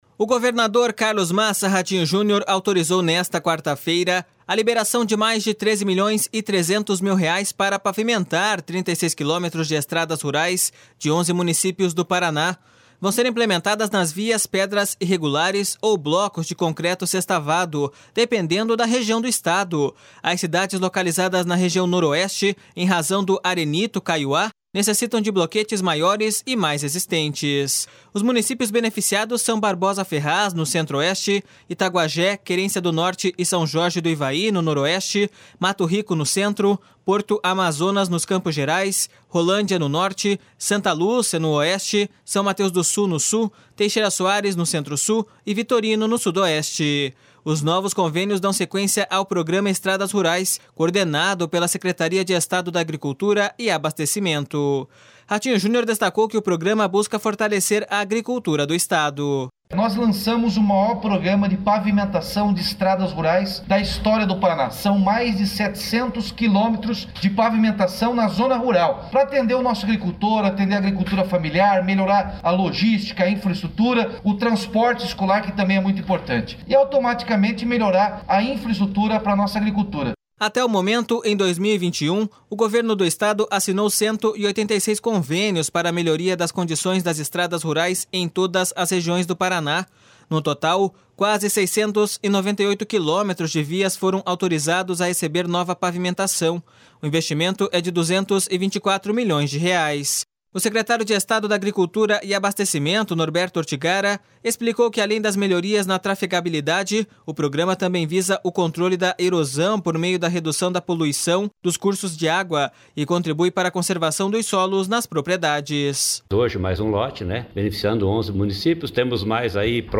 Os novos convênios dão sequência ao programa Estradas Rurais, coordenado pela Secretaria de Estado da Agricultura e Abastecimento. Ratinho Junior destacou que o programa busca fortalecer a agricultura do Estado.// SONORA RATINHO JUNIOR.//
O secretário de Estado da Agricultura e Abastecimento, Norberto Ortigara, explicou que além das melhorias na trafegabilidade, o programa também visa o controle da erosão por meio da redução da poluição dos cursos de água e contribui para a conservação dos solos nas propriedades.// SONORA NORBERTO ORTIGARA.//